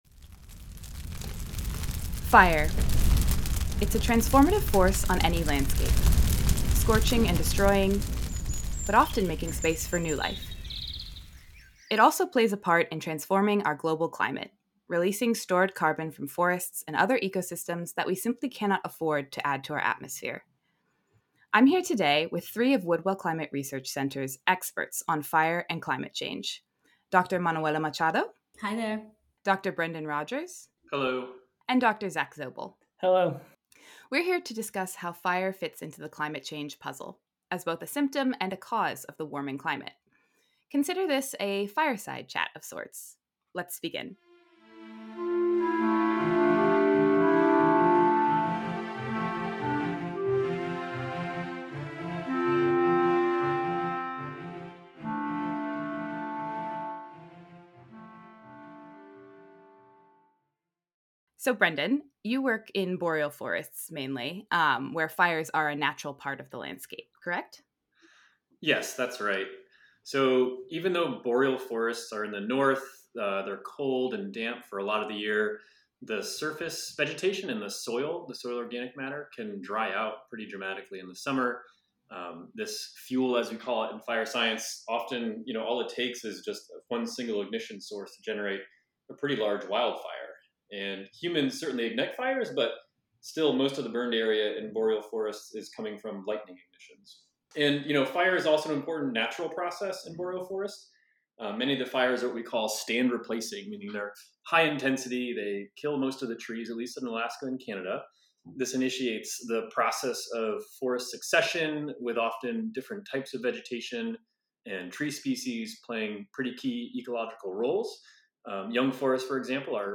Forest fires play an important role in the climate change story—as both a symptom of warming temperatures and a cause of them. Listen to three Woodwell Climate fire experts speak on the complex relationship between climate and fire.